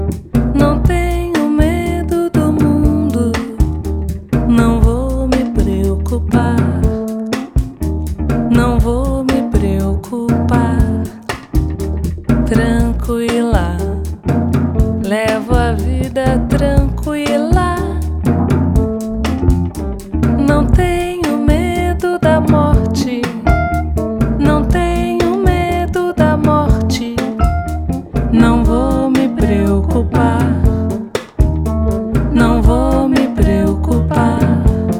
# MPB